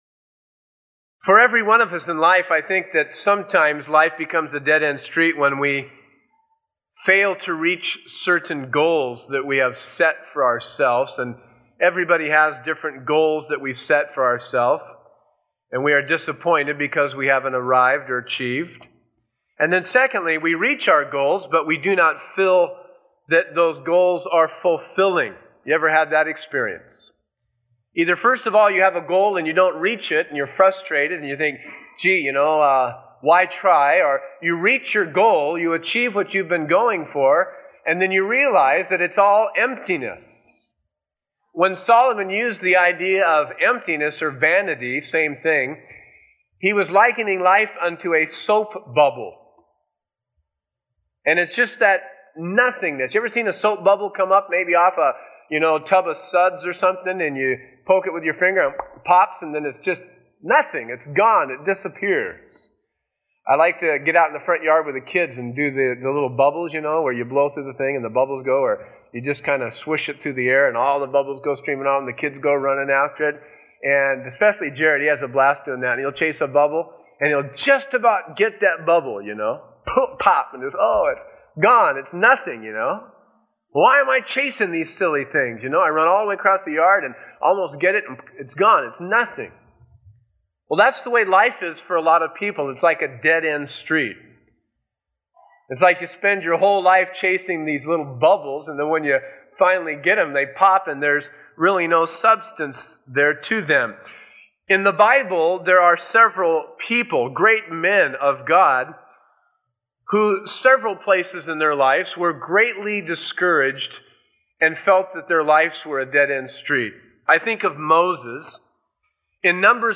taught at Calvary Chapel San Bernardino from January 1994 to March 1994.